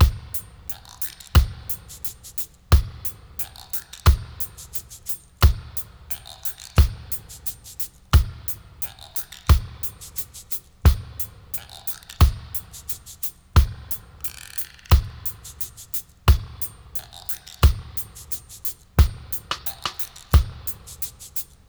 88-BRK+PERC-02.wav